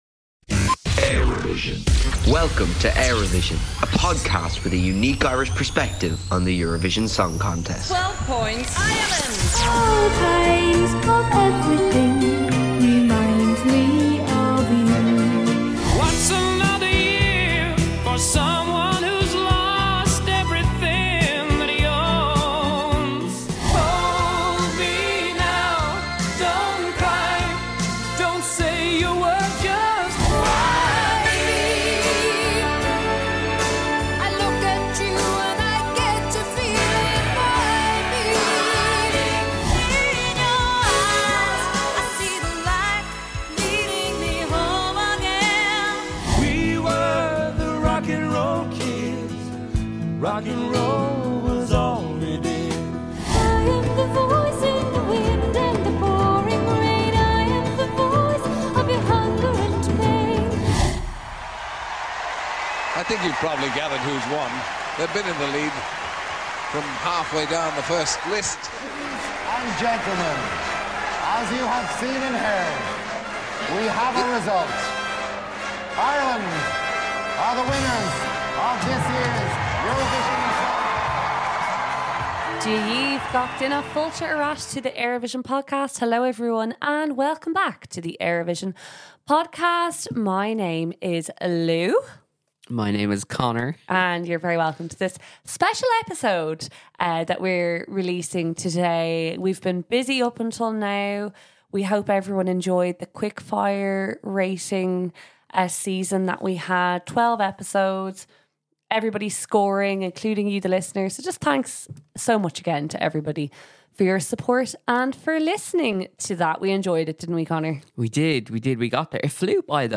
To help us celebrate, we're delighted to be joined by three iconic figures from the 1994 contest.
It is an honour to speak with the rock 'n' roll kids themselves, Paul Harrington & Charlie McGettigan.